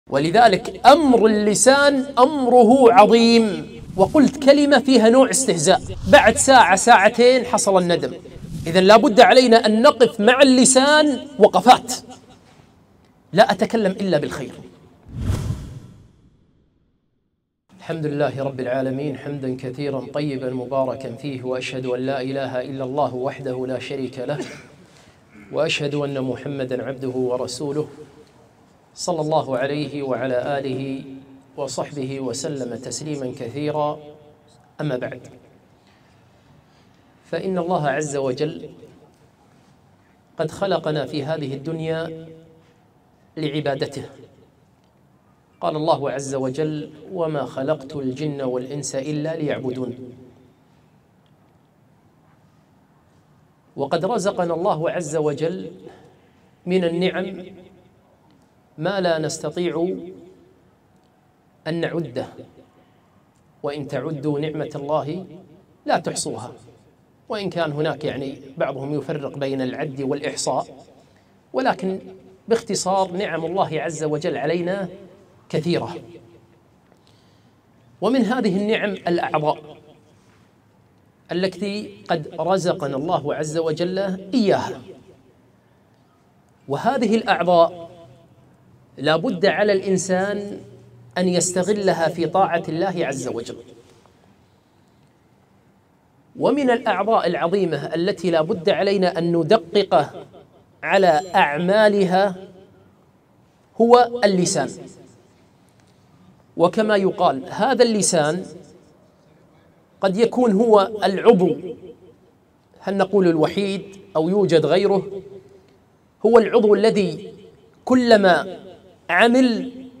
محاضرة - عضو اللسان